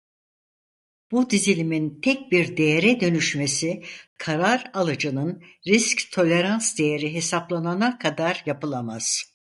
Read more risk (all senses) Frequency B2 Pronounced as (IPA) /risc/ Etymology Borrowed from French risque In summary From French risque.